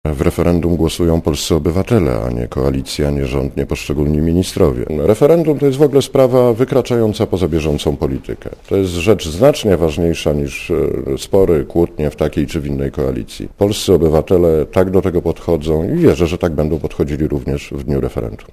Mówi Lech Nikolski (144Kb)